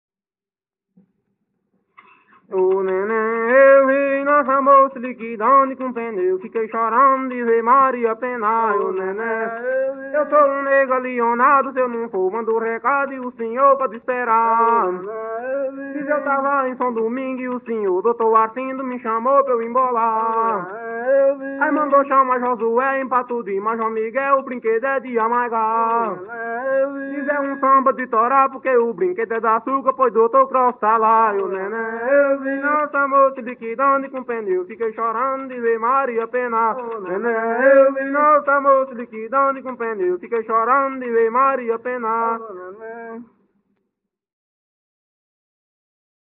Coco embolada/galope - ""O laia eu vi""